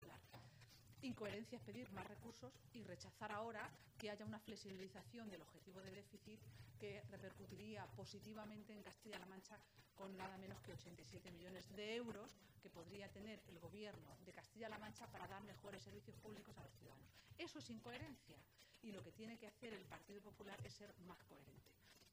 Cortes de audio de la rueda de prensa